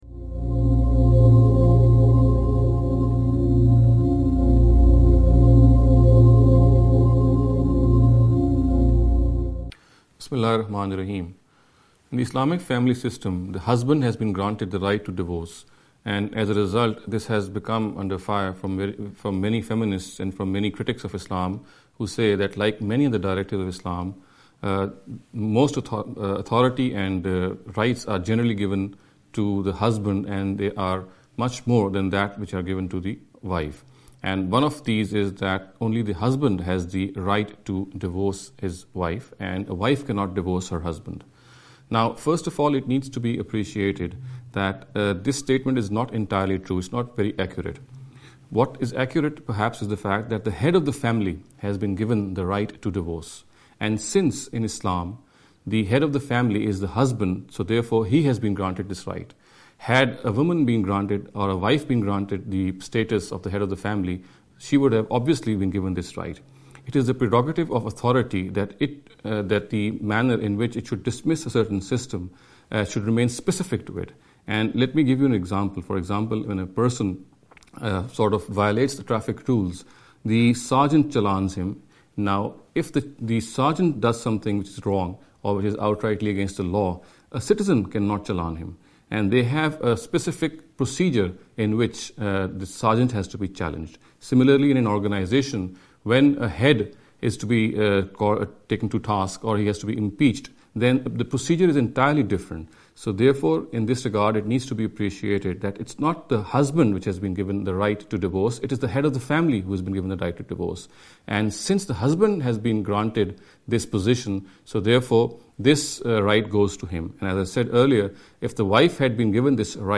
This lecture series will deal with some misconception regarding the Islam & Women.